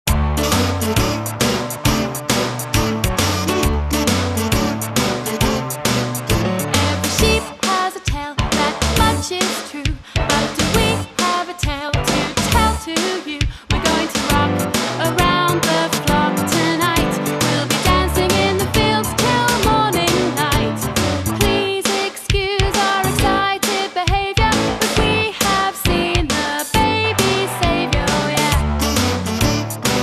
CD (Vocals and Backing Tracks)